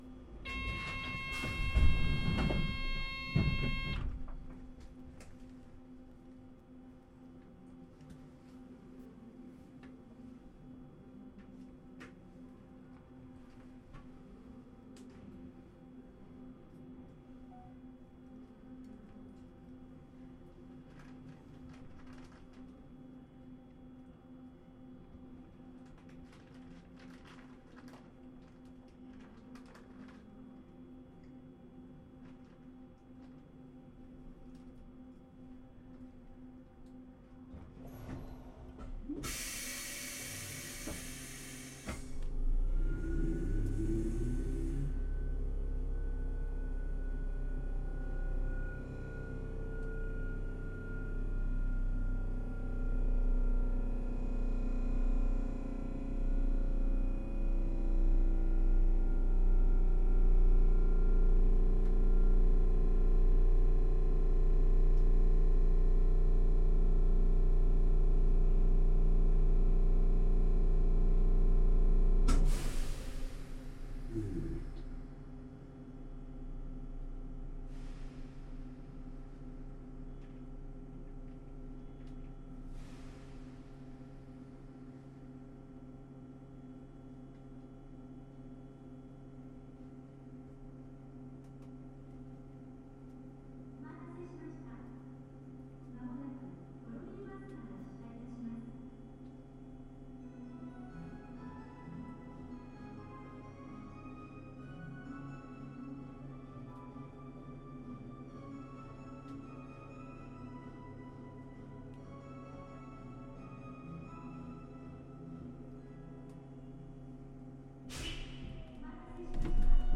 走行音(モータ付近)[tkk8000c.mp3/MP3-128kbps Stereo/8.96MB]
区間：大観峰→信号場→室堂 New!
※走行音録音のためのイベントでの録音です。こちらは車内放送なしです。2ファイルではマイクの場所や向きが違います。
種類：VVVFインバータ制御(東芝GTO第2世代、1C1M、発電ブレーキ)